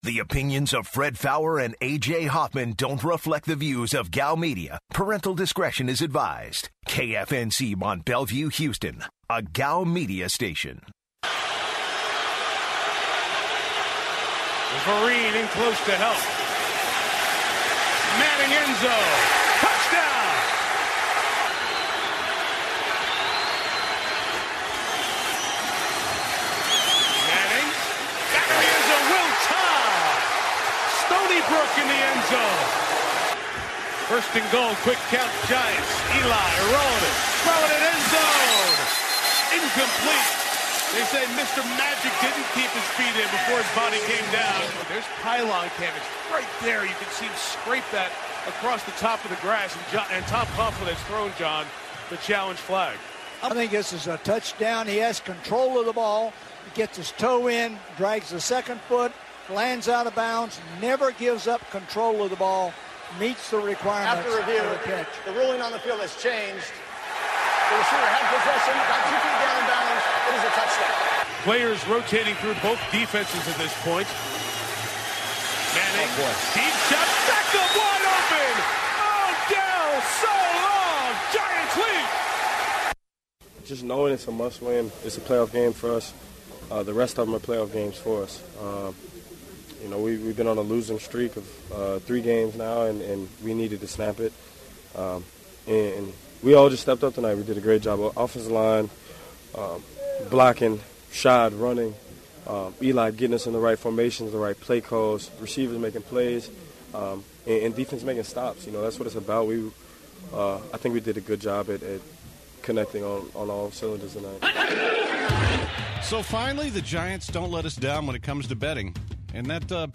To open the show, the guys react to MNF and signing Christmas cards. Plus, the guys discuss the possibility of the Houston Rockets moving Dwight Howard. The guys also take calls from Blitzers.